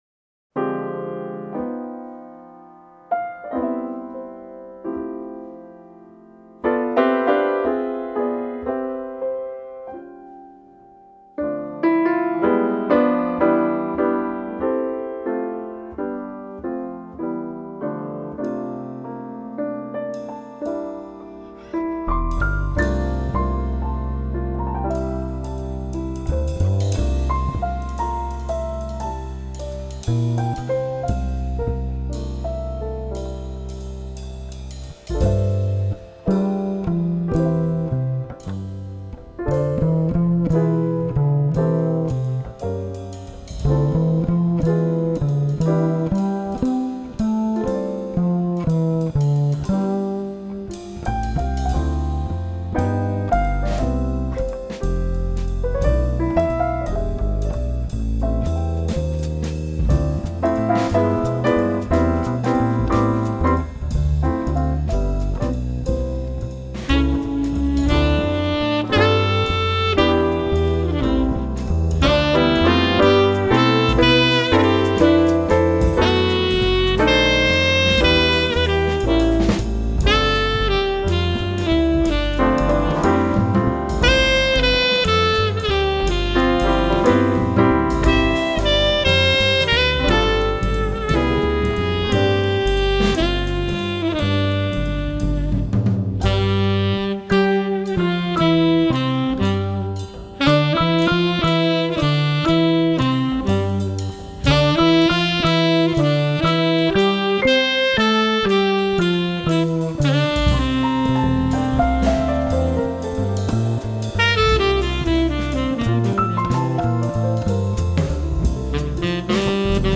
quartet